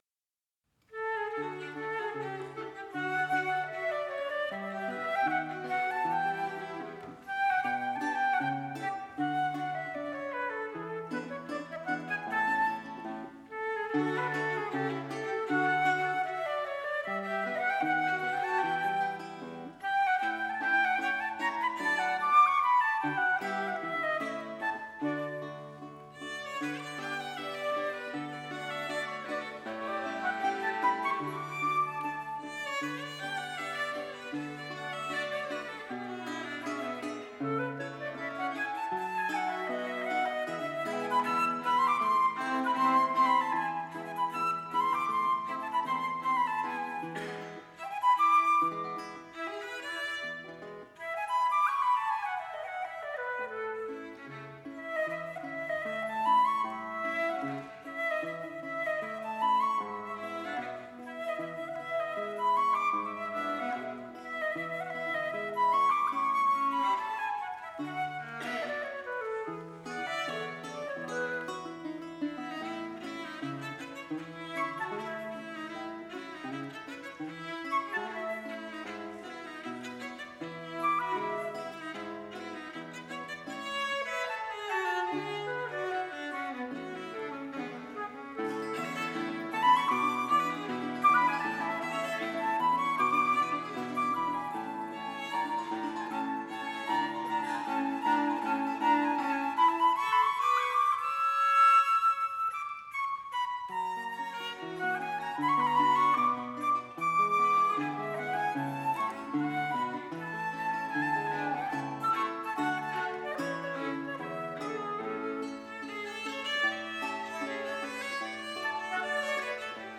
27/02/2012 11:40 Archiviato in: Live recording
flauto
viola
chitarra
Cappella dei Mercanti, Torino
Torino. Novembre 2008. Live recording.